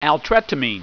Pronunciation
(al TRET a meen)